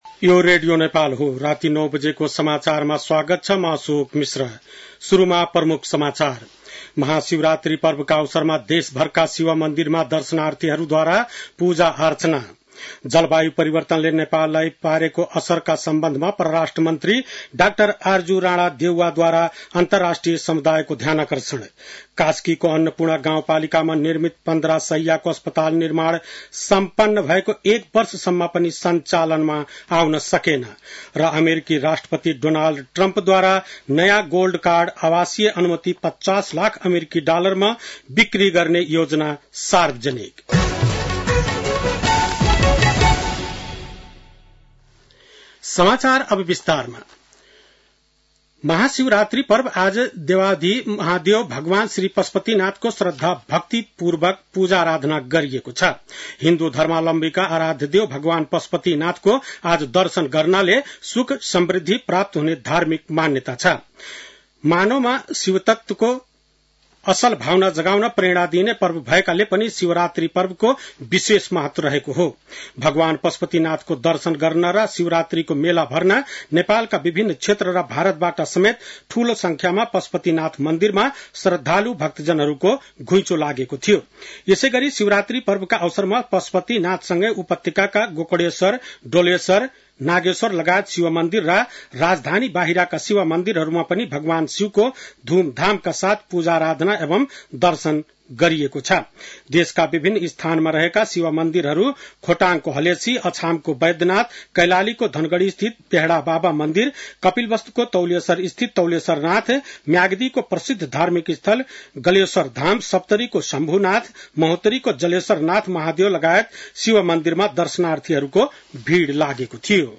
बेलुकी ९ बजेको नेपाली समाचार : १५ फागुन , २०८१